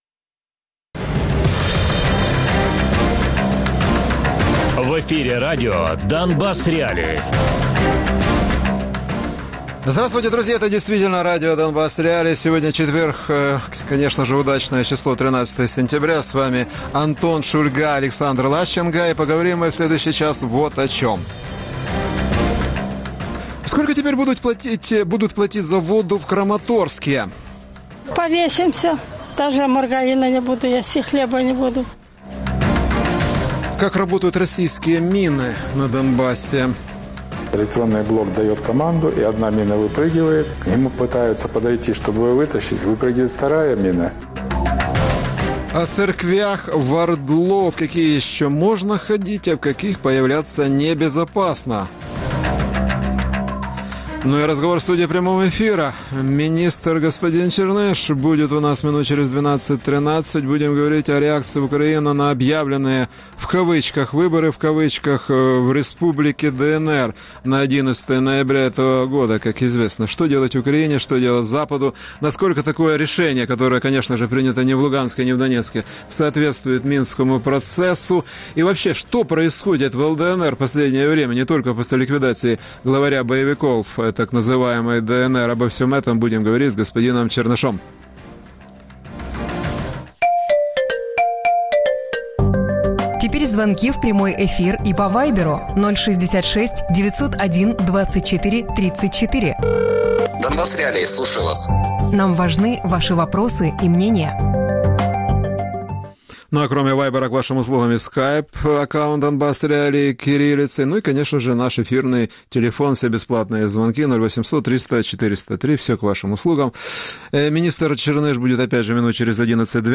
Гість: Вадим Черниш, міністр з питань тимчасово окупованих територій і внутрішньо переміщених осіб України. Радіопрограма «Донбас.Реалії» - у будні з 17:00 до 18:00. Без агресії і перебільшення. 60 хвилин про найважливіше для Донецької і Луганської областей.